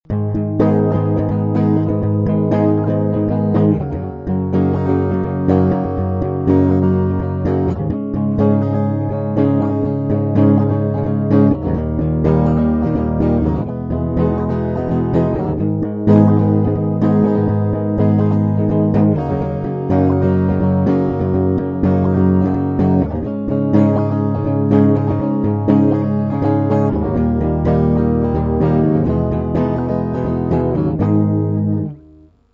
Проигрыш (Am - G6 - Dsus2 - E - Fmaj7):